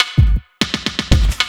LOOP06SD06-R.wav